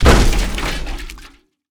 Heavy Object Impact 4.wav